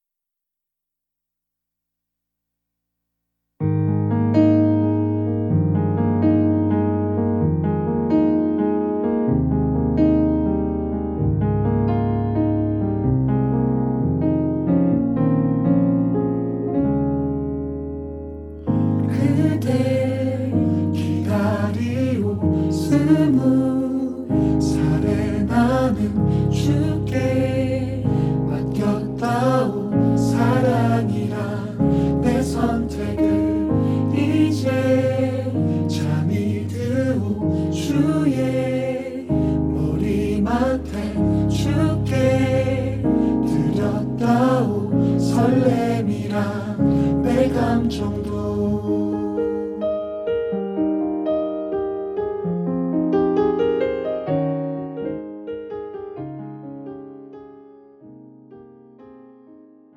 음정 -1키
장르 가요 구분
가사 목소리 10프로 포함된 음원입니다